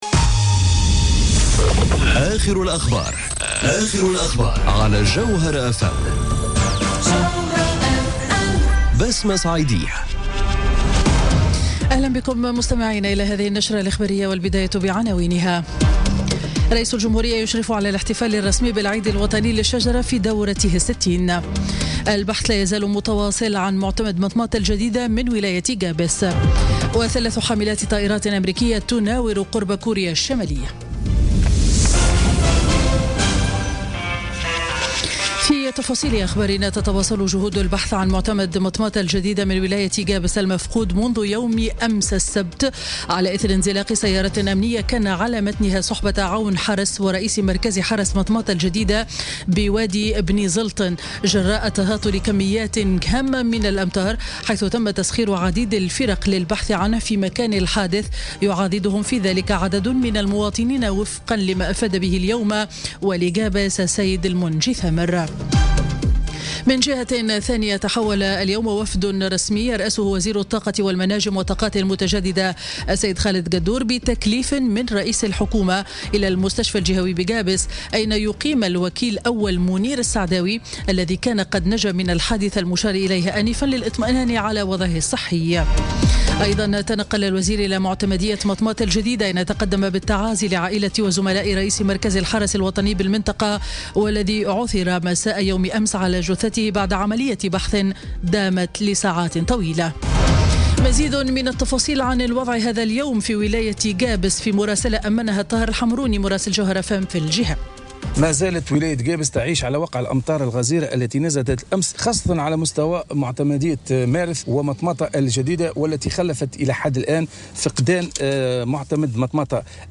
نشرة أخبار منتصف النهار ليوم الاحد 12 نوفمبر 2017